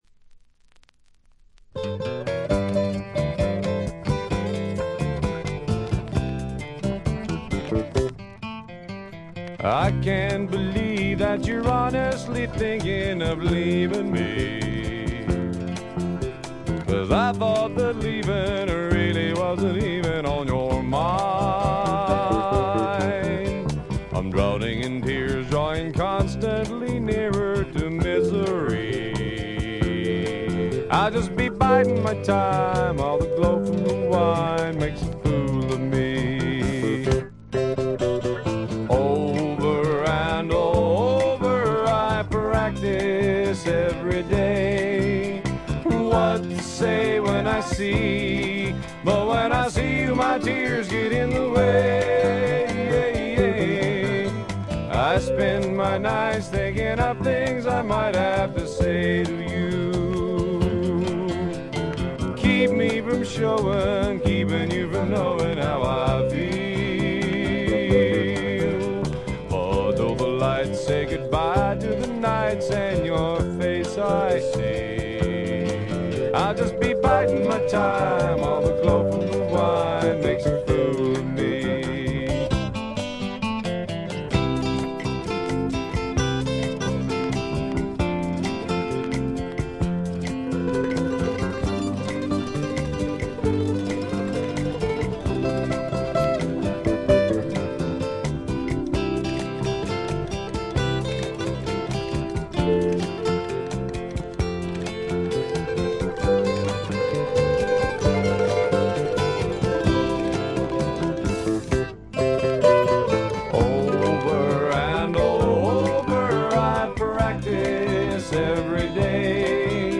チリプチ多め、散発的なプツ音も少々出ますが、普通に鑑賞できるものと思います。
知名度はいまいちながら実力派のいぶし銀のカントリーロックを聴かせます。
試聴曲は現品からの取り込み音源です。